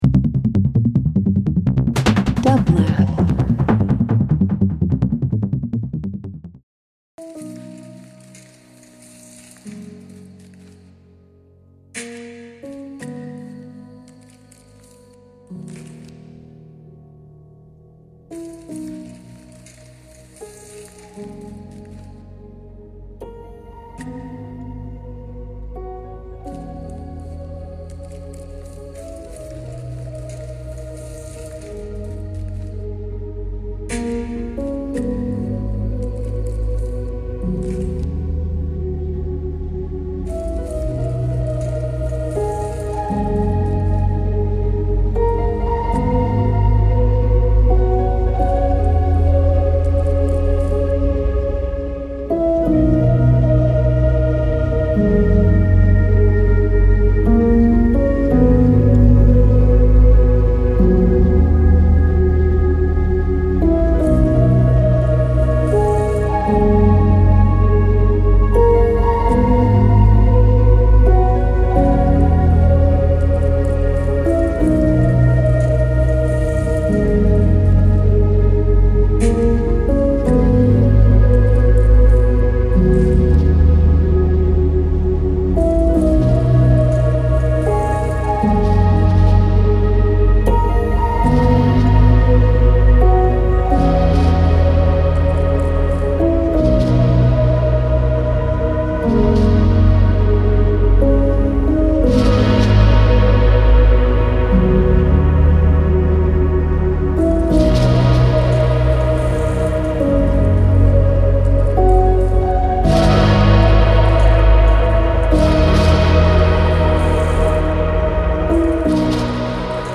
Using field recordings and evocative samples
Ambient Avant-Garde Hip Hop